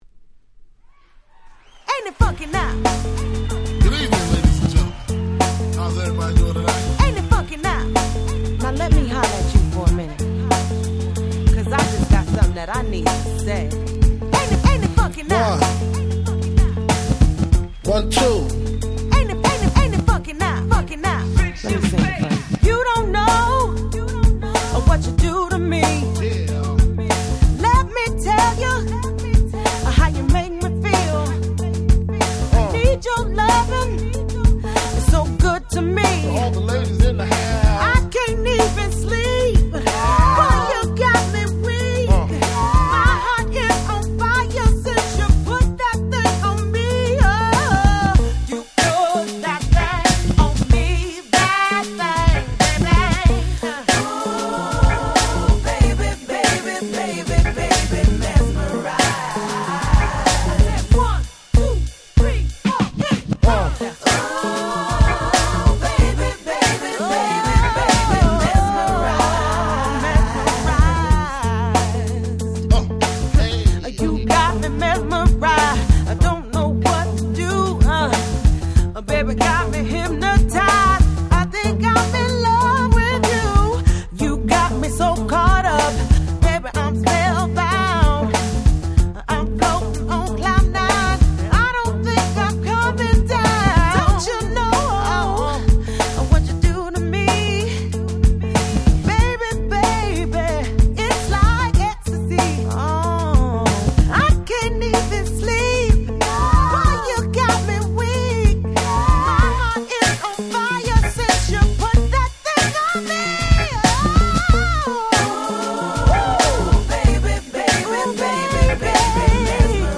05' Smash Hit R&B !!